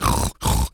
pgs/Assets/Audio/Animal_Impersonations/pig_sniff_deep_05.wav at master
pig_sniff_deep_05.wav